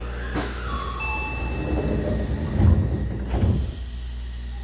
8000形ドアチャイム
5200形のそれとは異なり、後付けのチャイムながら3000形などと同じチャイムが設置されました。